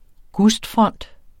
Udtale [ ˈgusdˌfʁʌnˀd ]